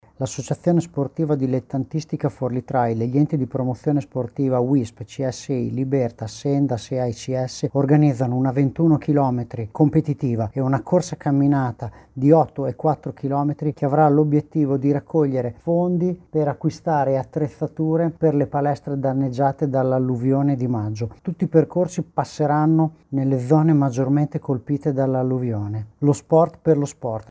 sonoro-sport-marathon.mp3